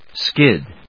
/skíd(米国英語)/